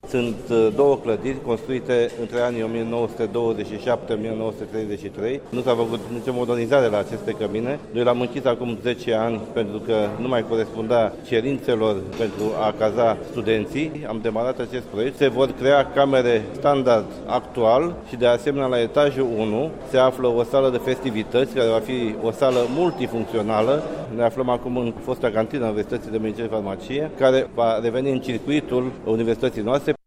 Mai multe detalii ne aduce Rectorul Universitatii de Medicina – Viorel Jinga:
Rectorul-Universitatii-de-Medicina-Viorel-Jinga.mp3